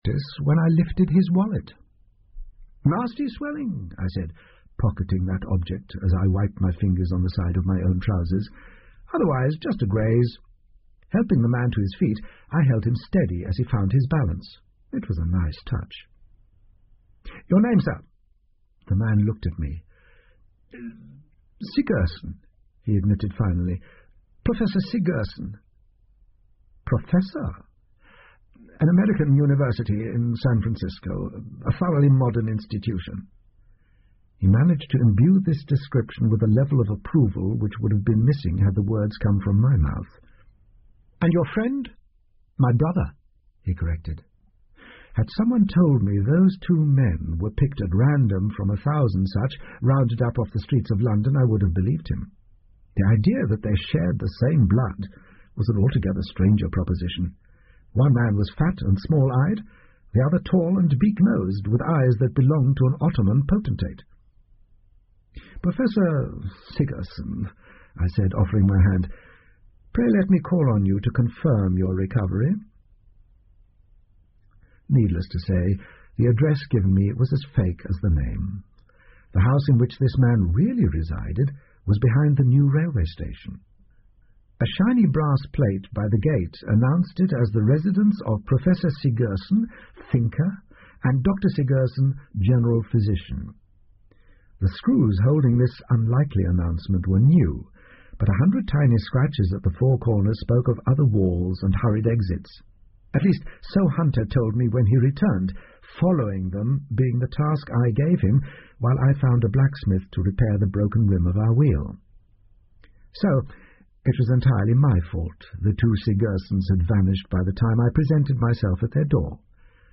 福尔摩斯广播剧 Cult-The Spy Retirement 4 听力文件下载—在线英语听力室